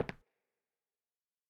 FootstepW5Left-12db.wav